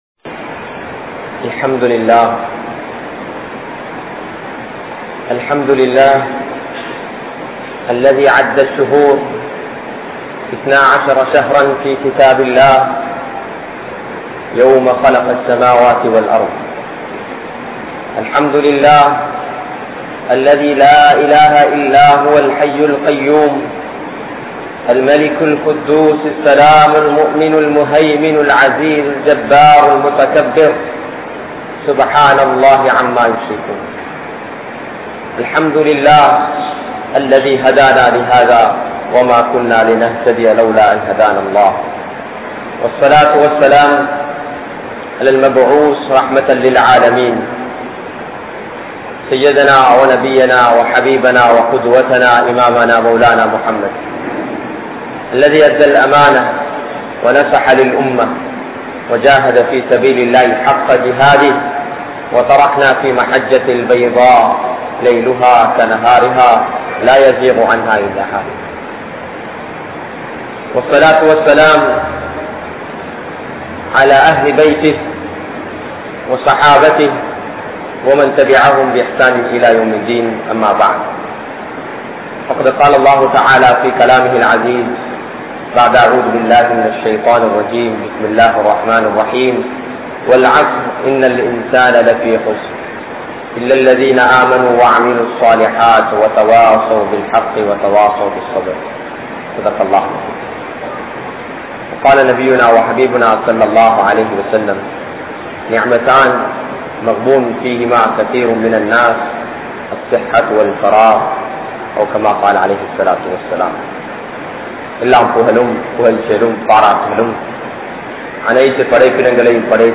Kaalam & Nearam(Time & Period) | Audio Bayans | All Ceylon Muslim Youth Community | Addalaichenai